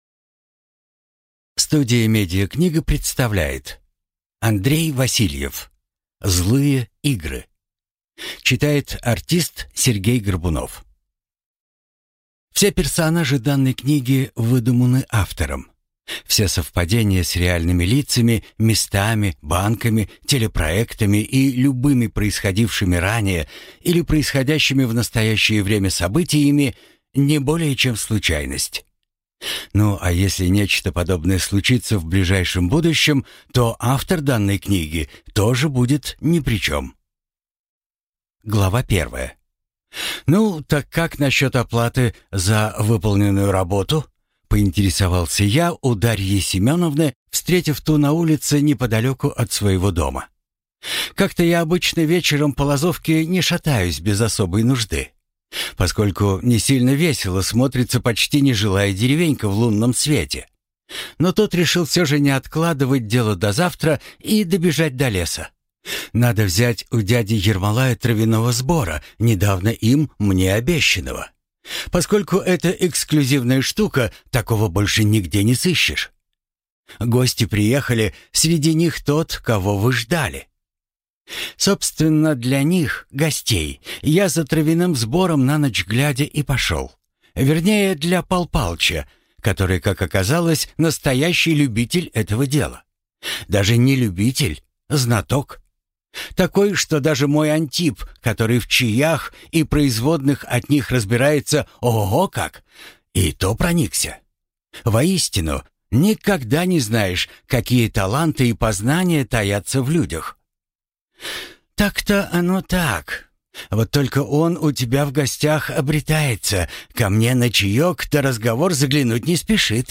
Аудиокнига Злые игры | Библиотека аудиокниг